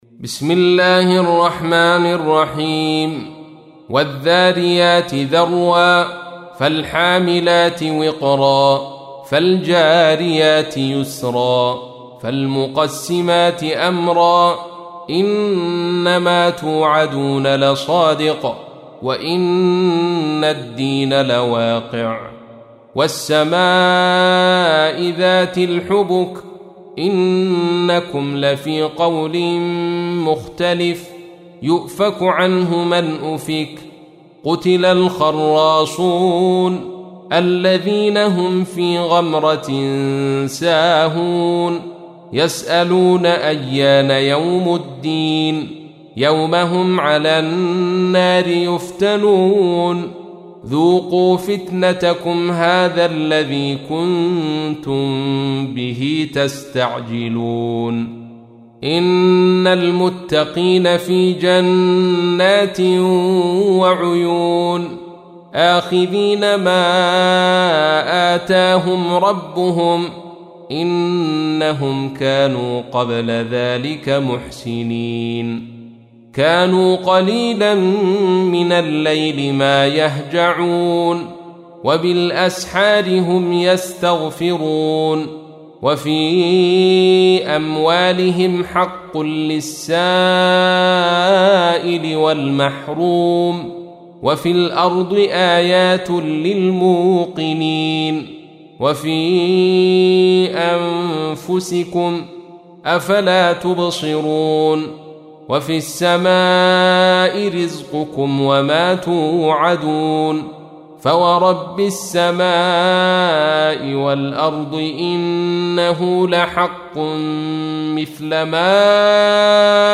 سورة الذاريات | القارئ عبدالرشيد صوفي